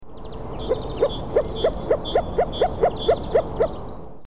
Moor Frog - Germany, Berlin-Blankenfelde